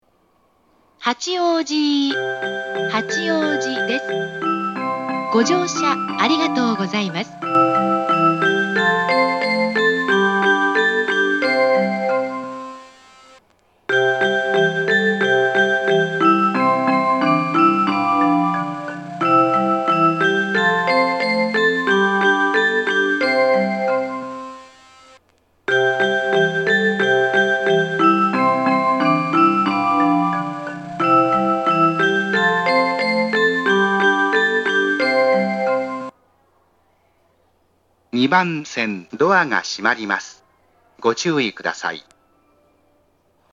発車メロディー
2.9コーラスです!停車時間がある列車が多いですが、余韻切りが多発します。特急の方が鳴りやすいです。
Hachioji-2Hassha.mp3